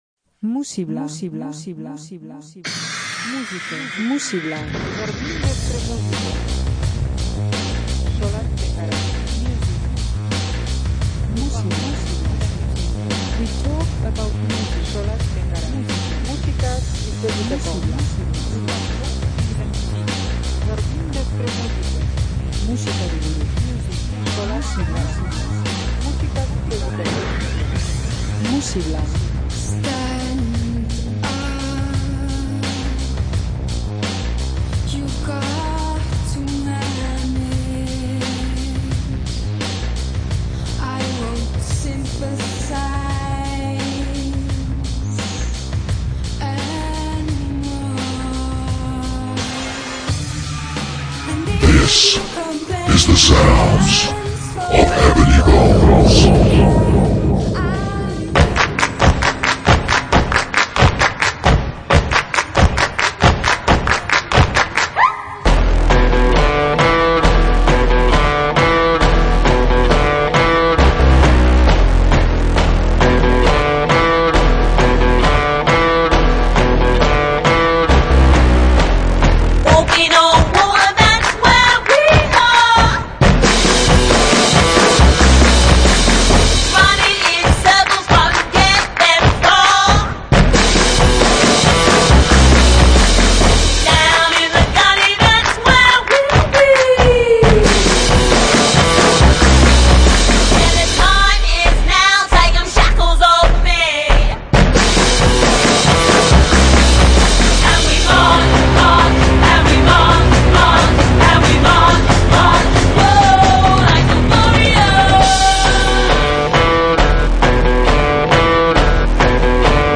disko musitaren ikuspegi berria hurbilduko dizuegu